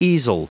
Prononciation du mot easel en anglais (fichier audio)
Prononciation du mot : easel